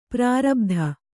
♪ prārabdha